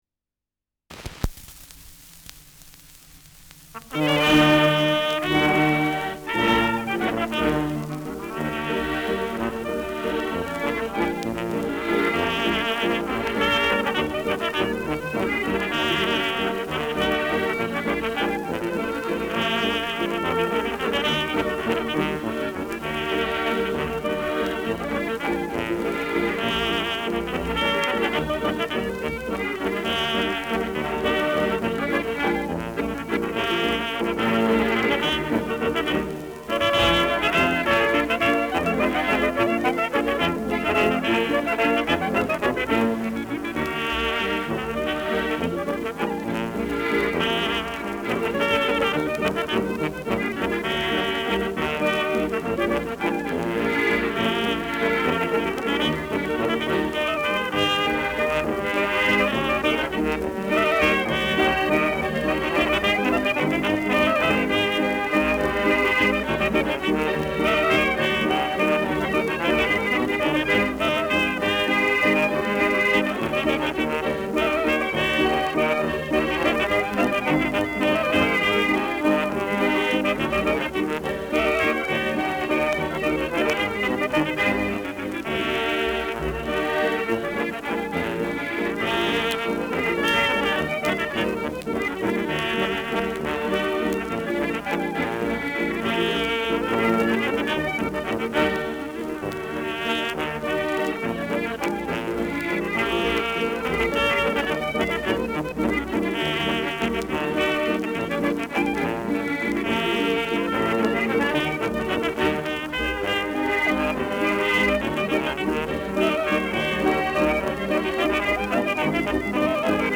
Schellackplatte
Tonrille: Kratzer 3 Uhr Leicht
D’ lustigen Pradler (Interpretation)